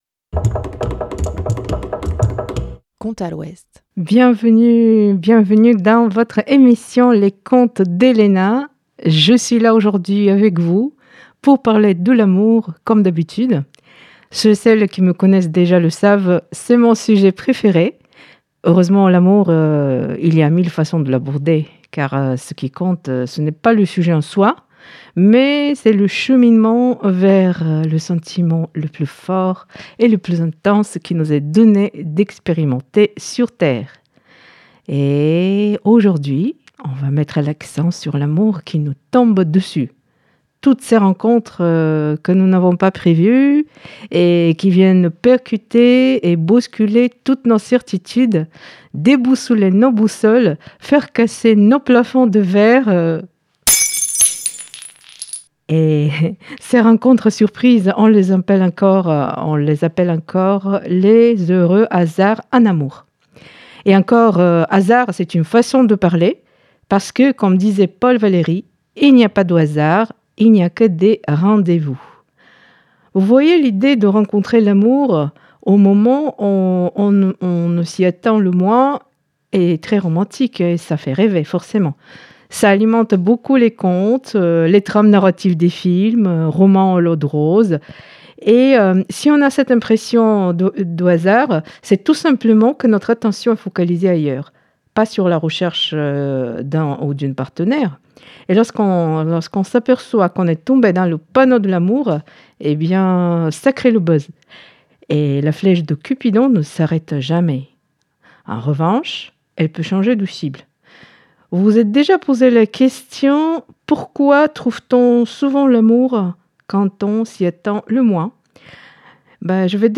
Pour mieux illustrer ce phénomène on vous fournit des exemples concrets à travers la lecture de plusieurs contes qui nous montrent qu'au royaume de l'amour tout est possible !